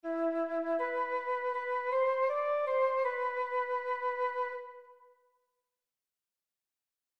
A 2ª voz móvese dentro do intervalo cantado na anterior actividade: "Son cinco son cinco son" Para familiarizarte coa tesitura da 1ª voz, canta o seguinte exercicio: 3exer.mp3